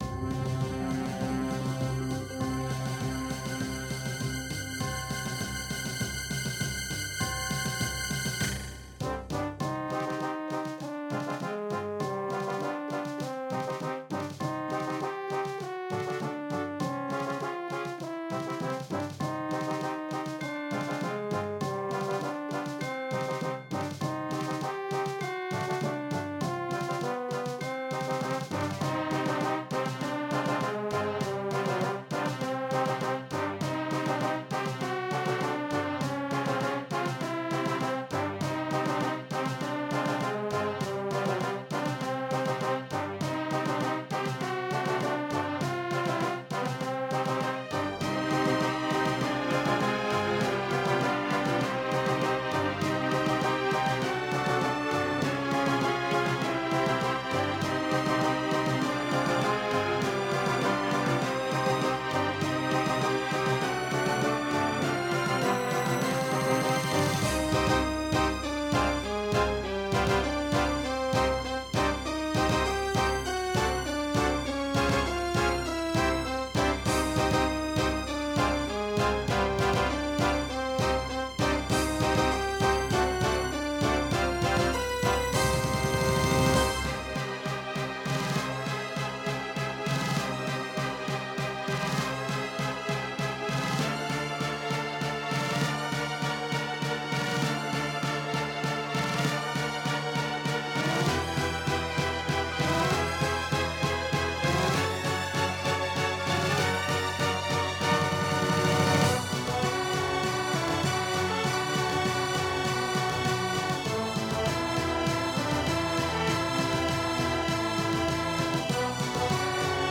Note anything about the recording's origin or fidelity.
AZTech SoundGalaxy Waverider 32 Plus Games Descent Some records contain clicks.